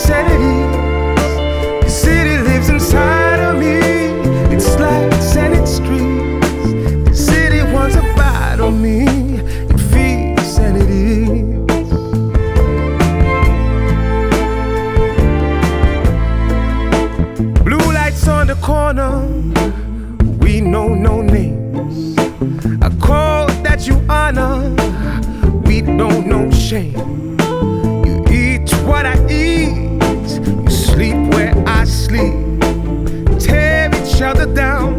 # Contemporary R&B